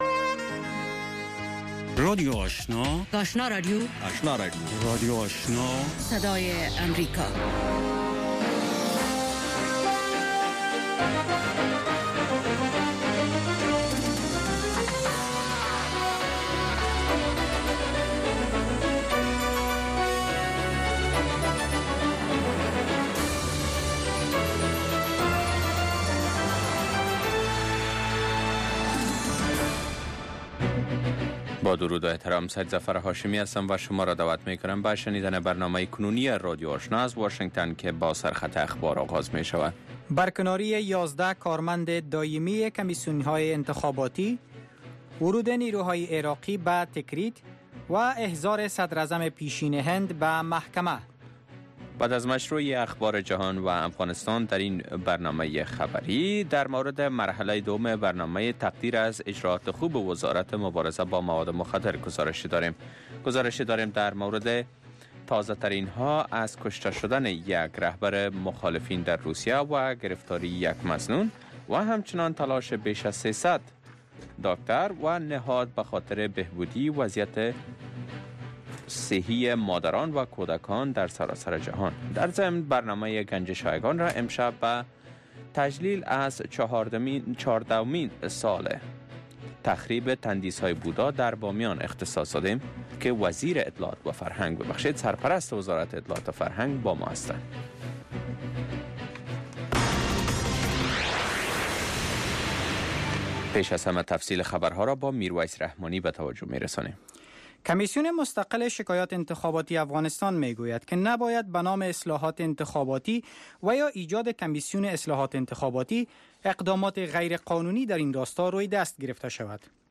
برنامه گفت و شنود/خبری اتری - گفتمان مشترک شما با آگاهان، مقام ها و کارشناس ها.